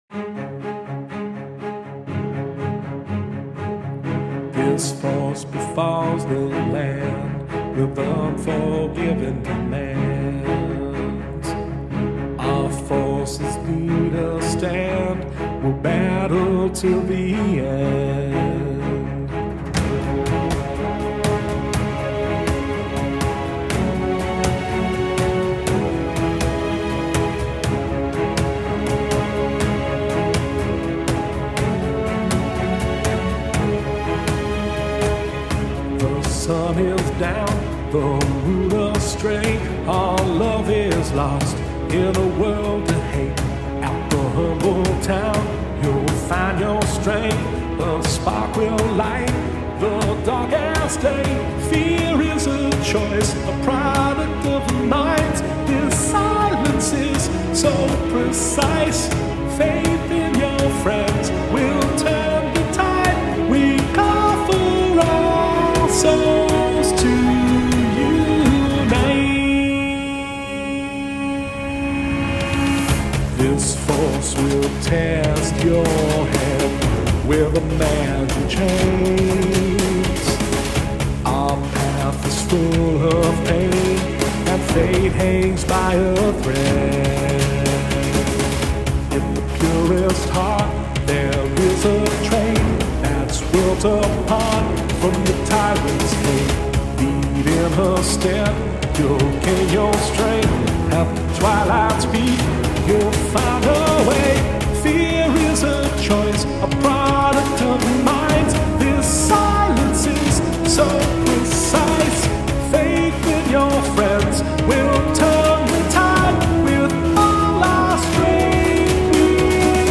Instrumental
Genre: Hybrid Orchestral, Vocal (Lyrics)